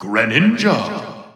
The announcer saying Greninja's name in English releases of Super Smash Bros. 4 and Super Smash Bros. Ultimate.
Greninja_English_Announcer_SSB4-SSBU.wav